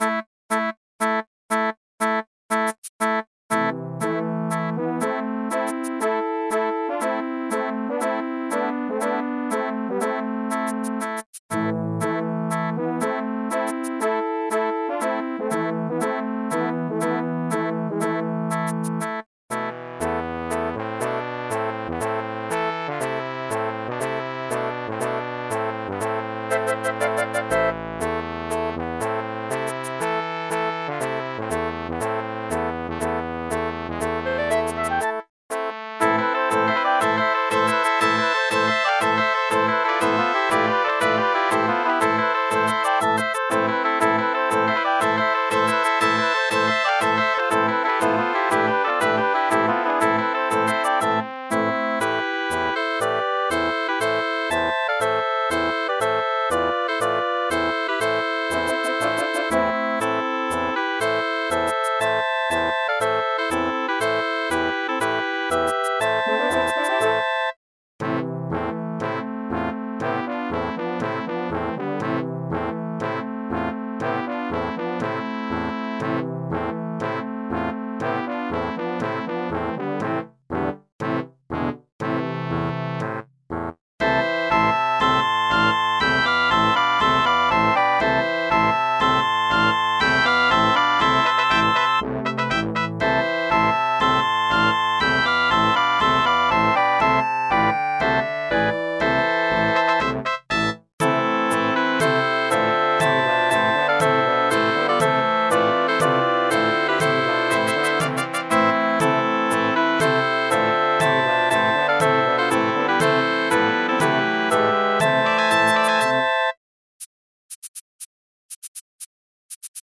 for Combined Brass and Woodwind Quintets